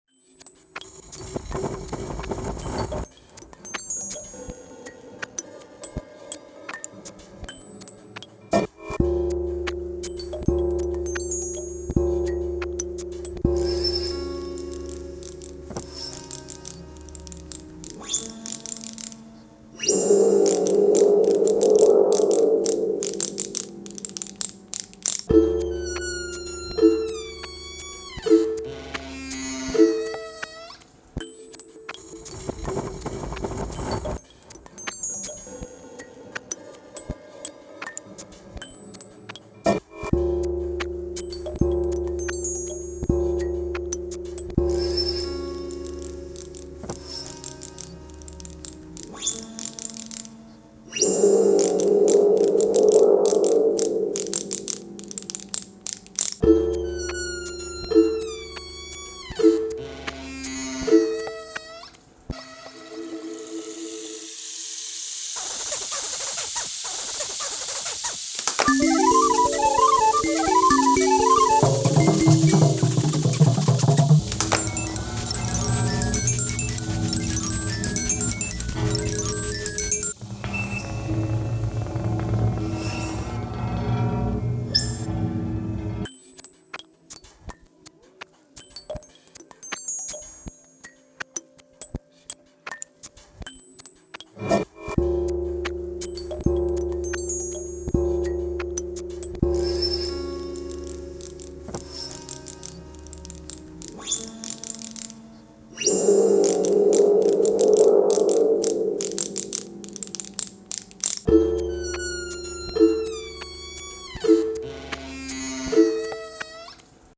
En bonus, un cadeau sonore (issu de l’atelier musique et informatique du GAM) :